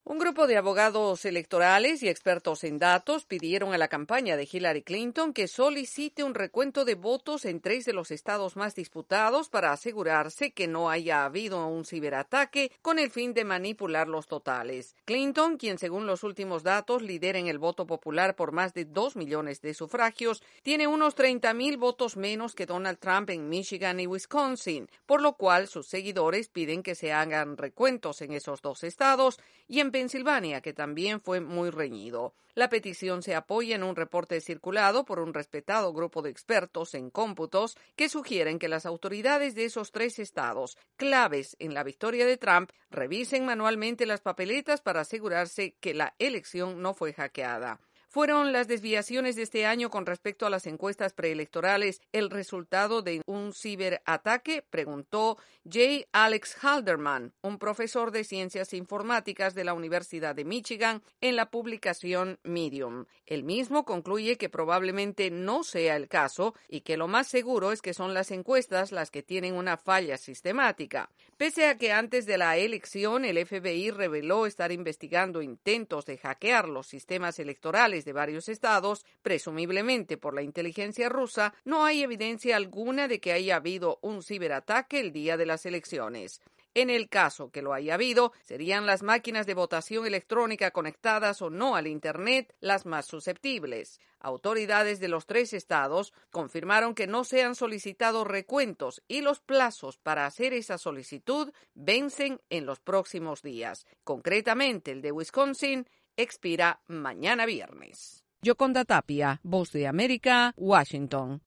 EE.UU. Sugieren recuento de votos en tres Estados Un grupo de expertos sugieren a Hillary Clinton pedir un recuento de votos donde el resultado es ajustado y en el marco del peligro de hackeo. Desde la Voz de América en Washington DC informa